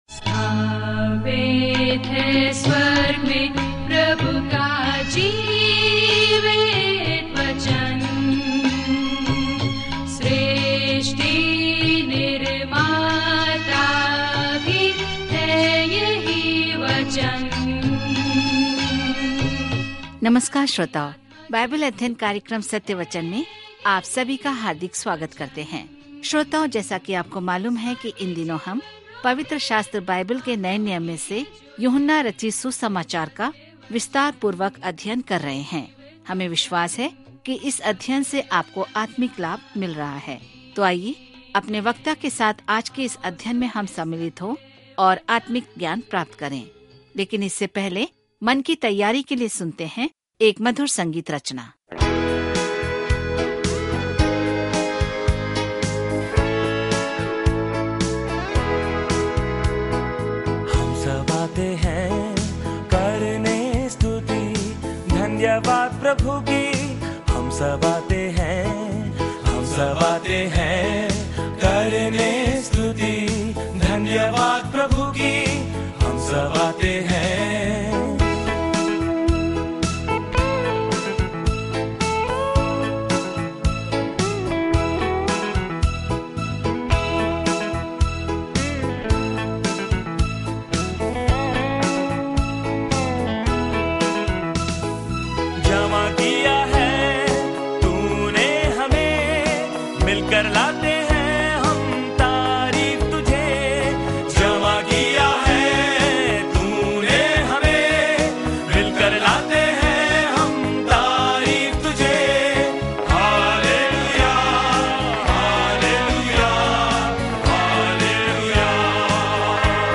पवित्र शास्त्र यूहन्ना 8:1-12 दिन 14 यह योजना प्रारंभ कीजिए दिन 16 इस योजना के बारें में जॉन द्वारा बताई गई अच्छी खबर अन्य सुसमाचारों से अद्वितीय है और इस बात पर ध्यान केंद्रित करती है कि हमें यीशु मसीह में विश्वास क्यों करना चाहिए और इस नाम पर जीवन कैसे जीना चाहिए। जॉन के माध्यम से दैनिक यात्रा करें क्योंकि आप ऑडियो अध्ययन सुनते हैं और भगवान के वचन से चुनिंदा छंद पढ़ते हैं।